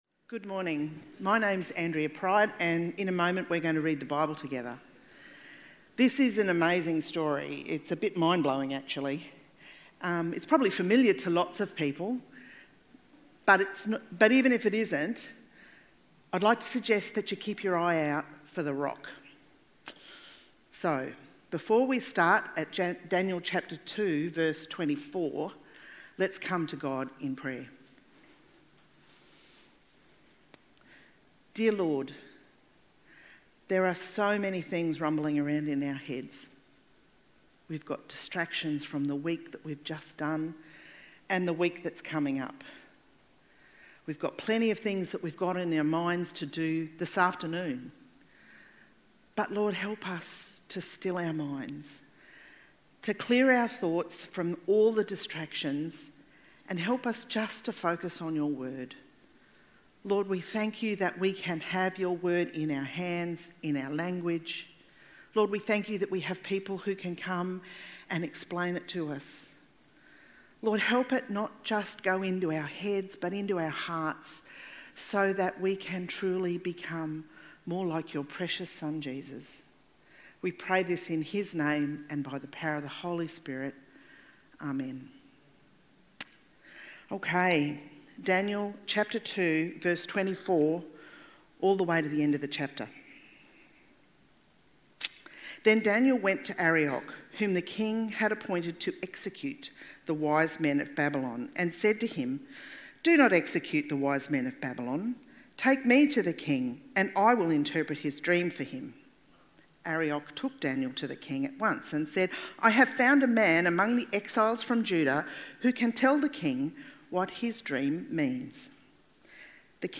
Week2-EternalKingdom-TALK.mp3